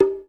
14 CONGA.wav